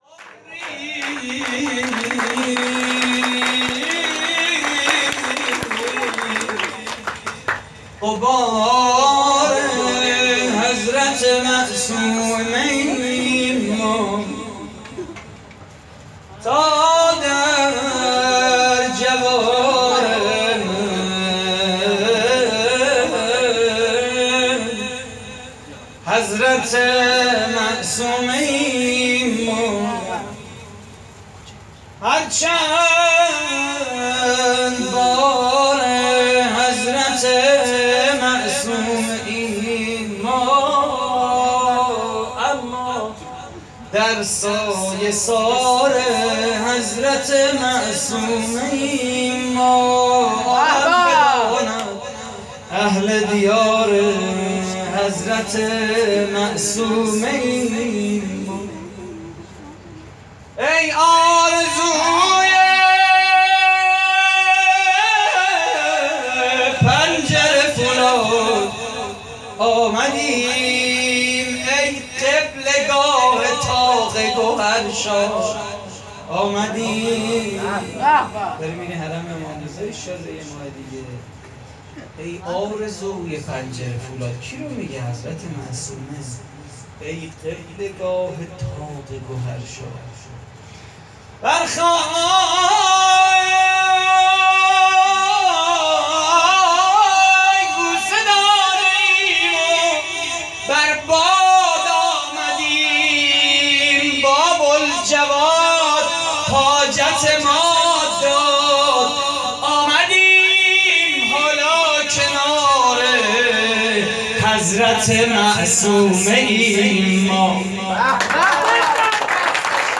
مدیحه: ای آرزوی پنجره فولاد آمدیم
مراسم جشن ولادت حضرت معصومه (س)